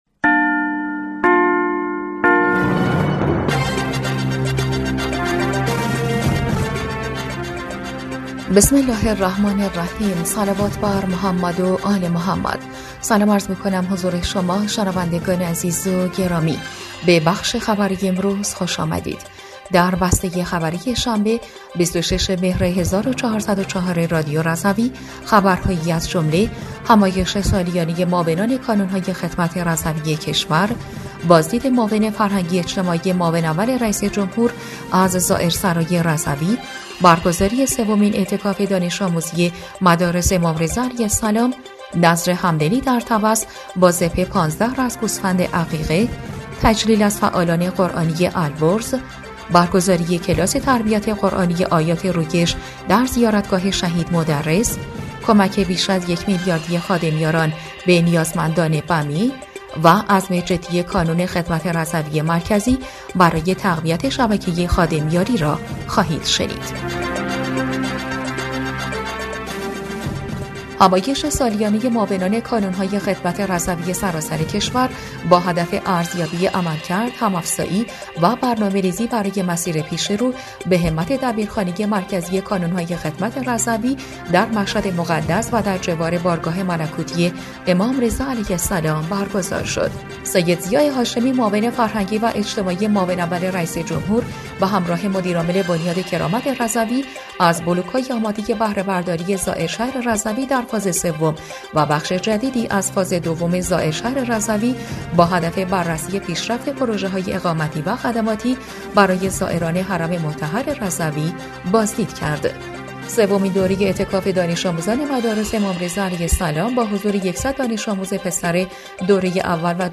بسته خبری ۲۶ مهر ۱۴۰۴ رادیو رضوی؛